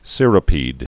(sîrə-pēd) also cir·ri·ped (-pĕd)